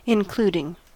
Ääntäminen
Ääntäminen US Haettu sana löytyi näillä lähdekielillä: englanti Including on sanan include partisiipin preesens.